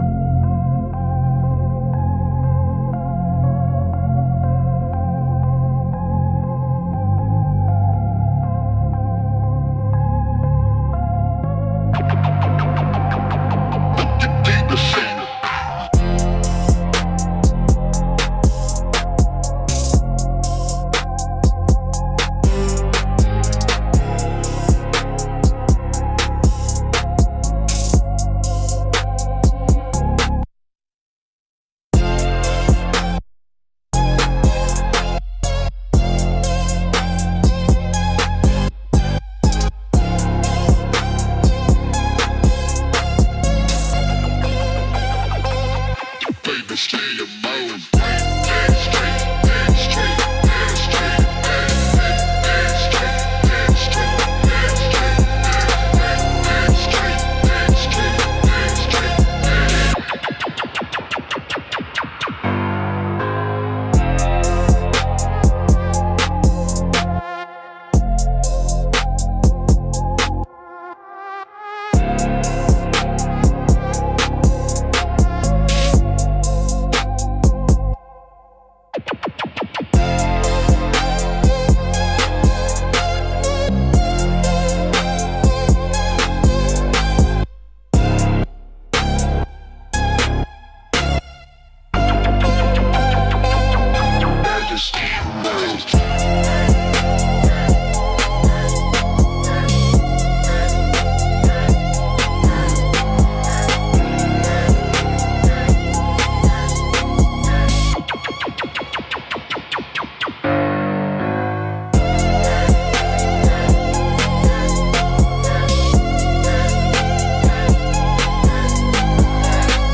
Instrumentals - Midnight Mass